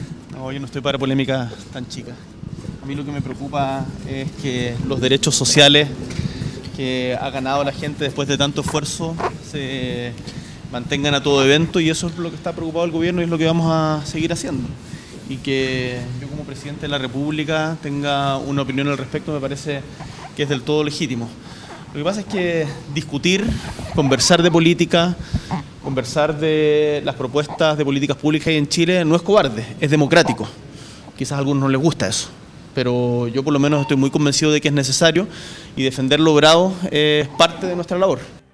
Al ser consultado por la prensa sobre la polémica, el Mandatario afirmó que su preocupación se centra en que los “derechos sociales” ganados por la ciudadanía “se mantengan a todo evento”.